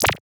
Sci-Fi Click.wav